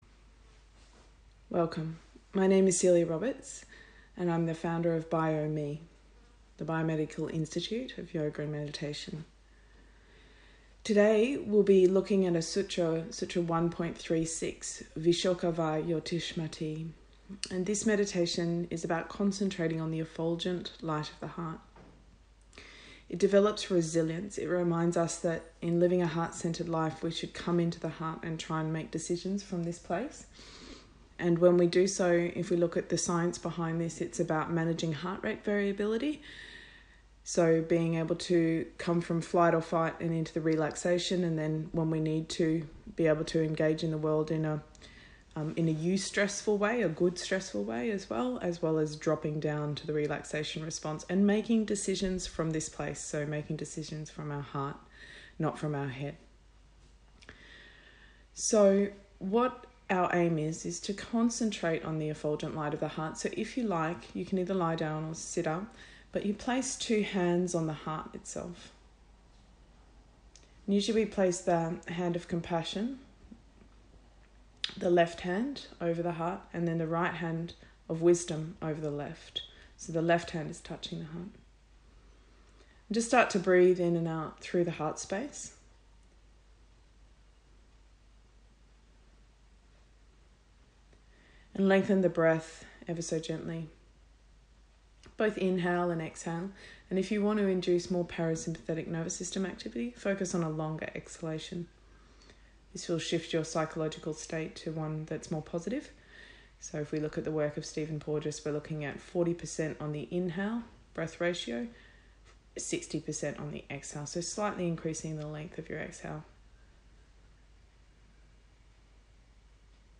Primary Use: meditation, breathing, grief and loss